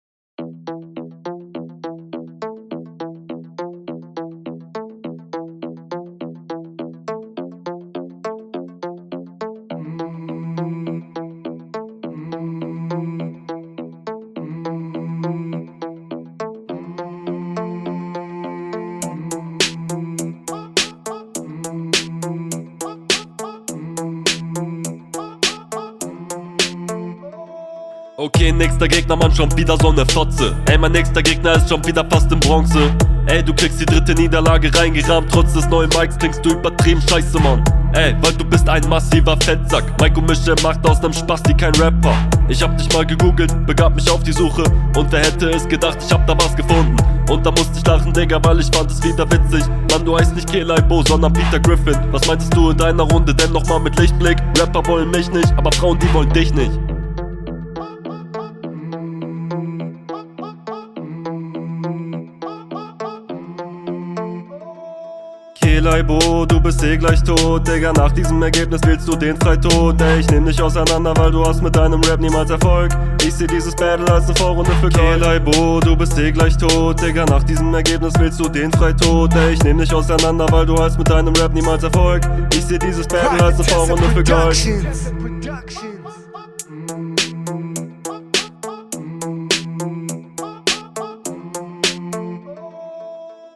Bissl bessere Soudnqualität, als in deiner RR1, aber Hook holt mich etwas raus.